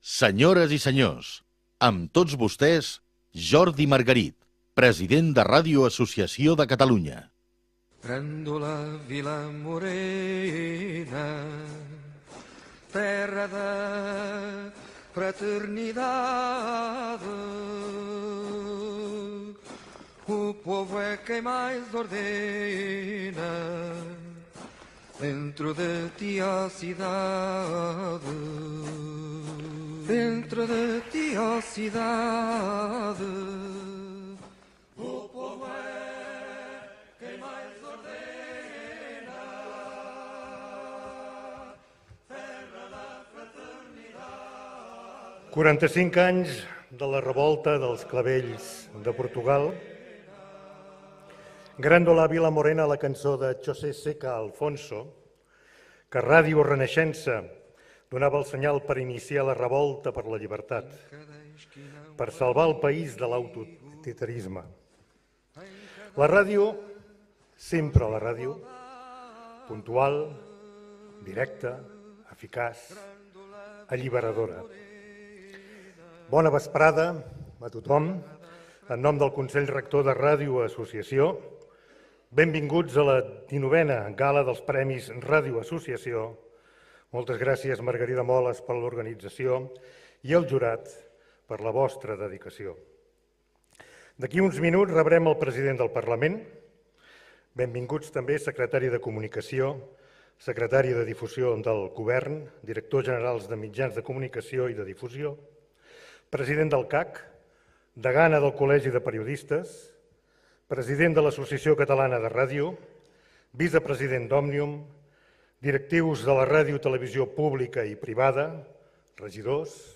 Lliurament de premis fet a l'Auditori de l'ONCE a Barcelona i retransmès en directe pel canal de You Tube de Ràdio Associació de Catalunya.